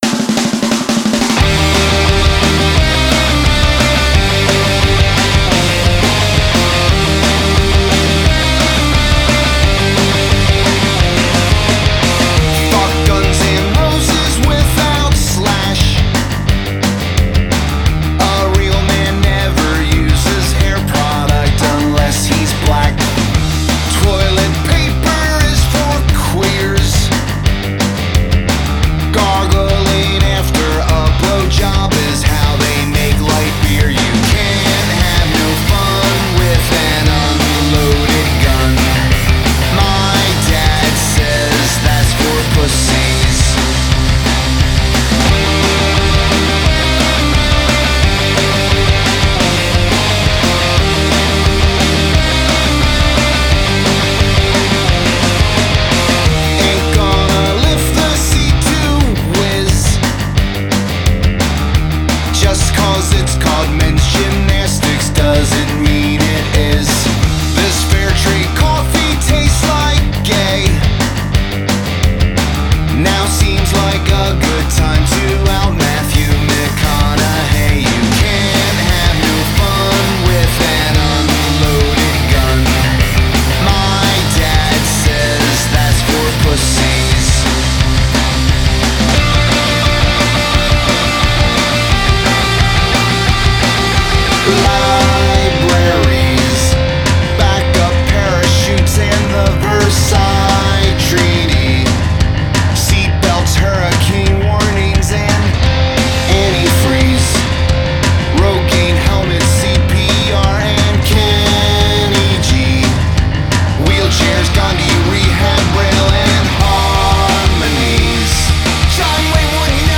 Жанр: Alternative, Rock